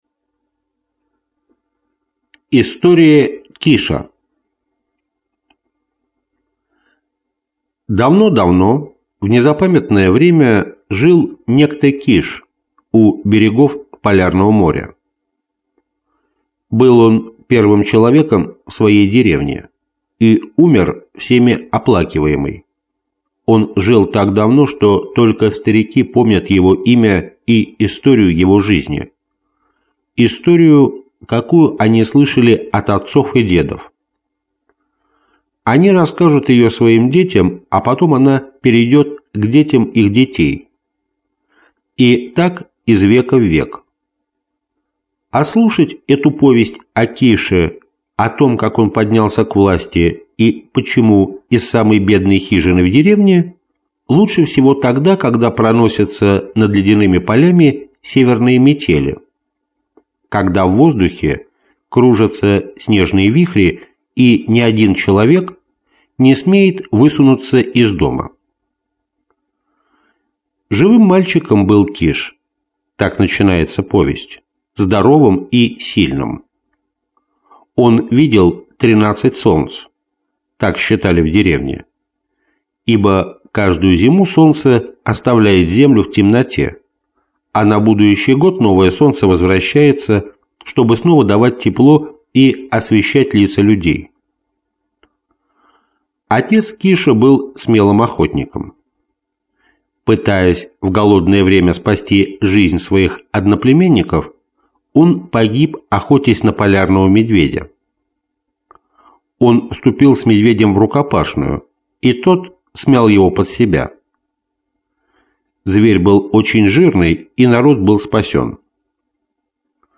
Аудиокнига Сказки южных морей. Любовь к жизни | Библиотека аудиокниг